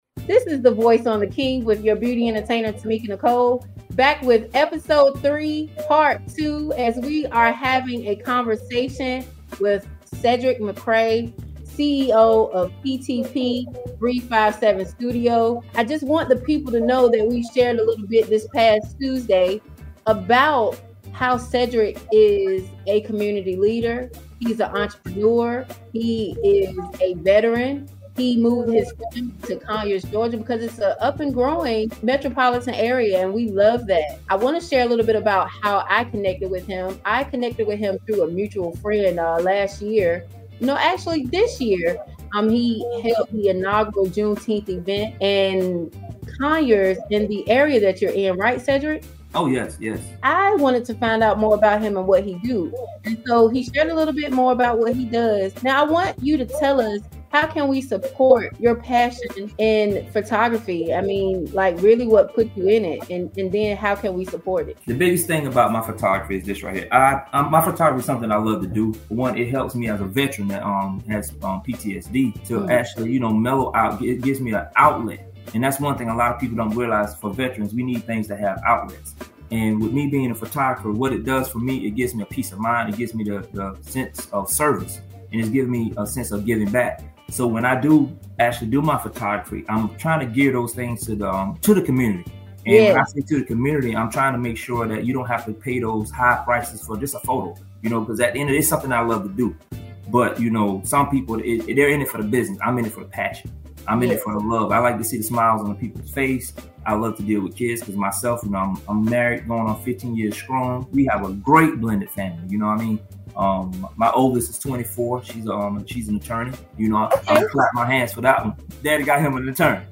The Voice is a 2 Part Segment Show where local and national leaders share their stories with the world!